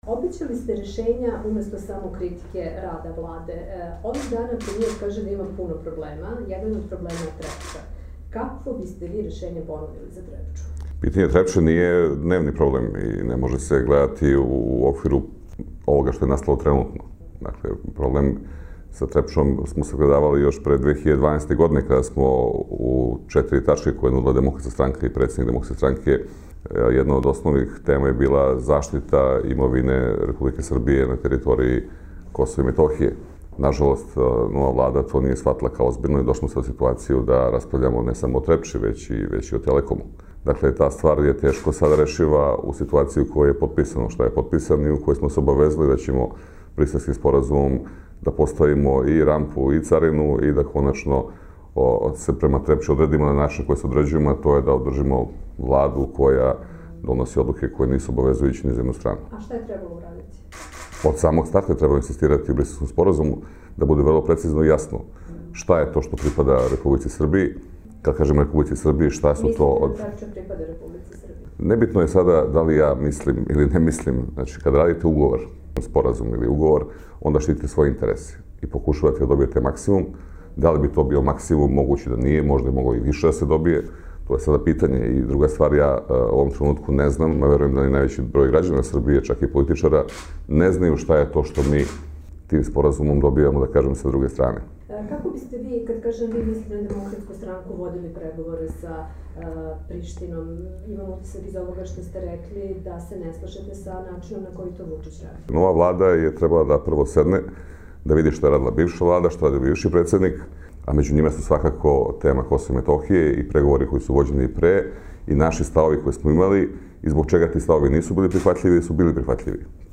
Intervju nedelje: Dragan Šutanovac